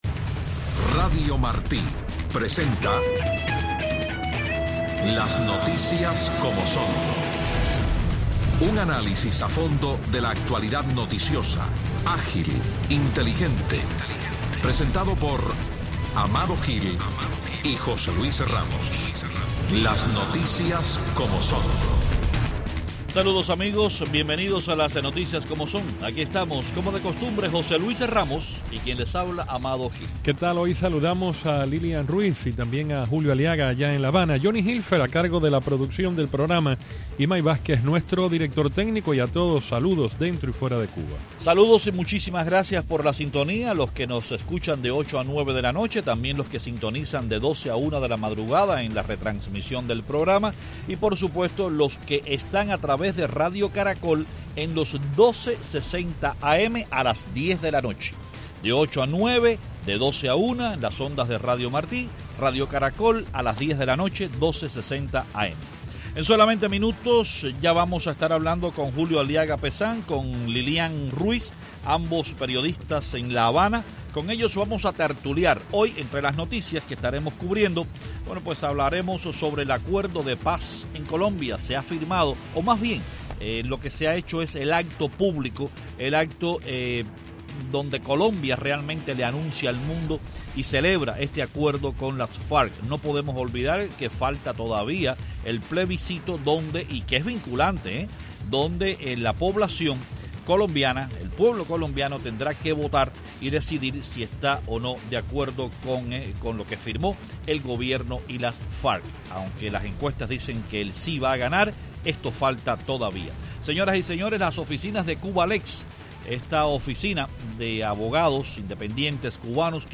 En Tertulia
ambos desde La Habana